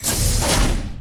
doorsopen.wav